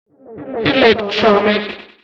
Звуки электронного голоса
Звук синтезированного женского голоса компьютера